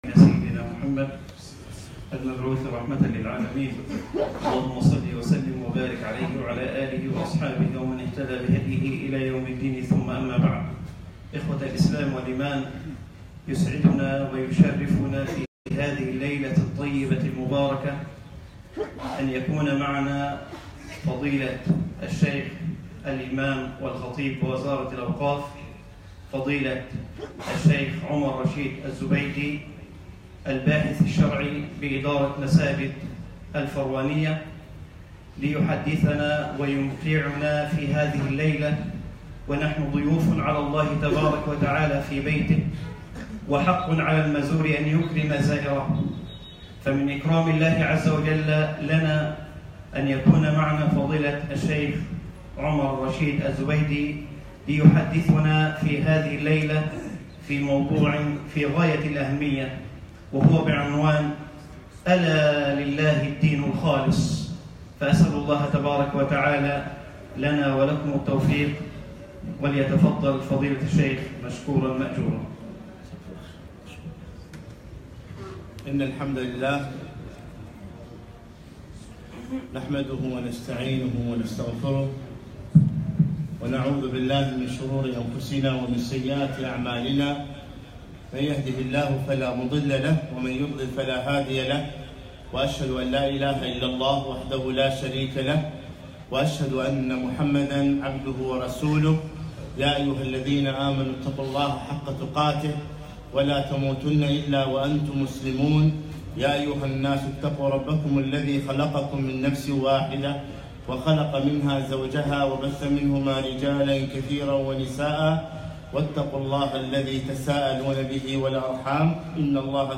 محاضرة - ألا لله الدين الخالص